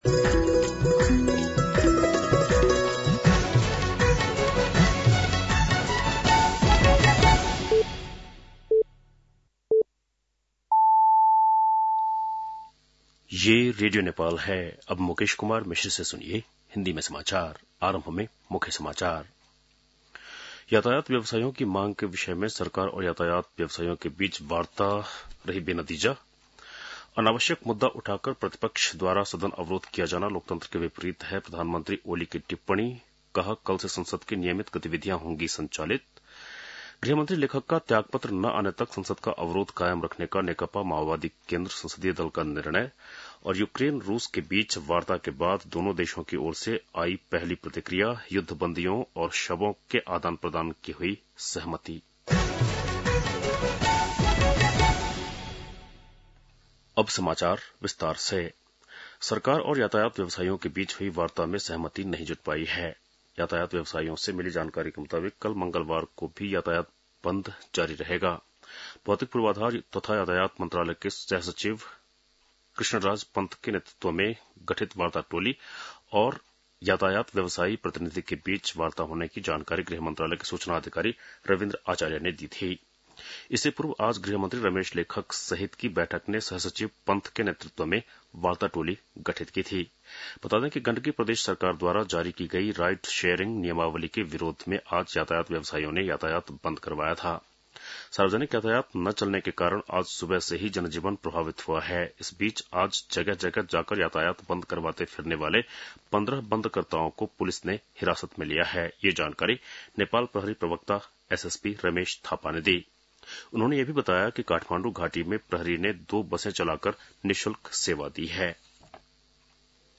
बेलुकी १० बजेको हिन्दी समाचार : १९ जेठ , २०८२
10-PM-Hindi-NEWS-1-1.mp3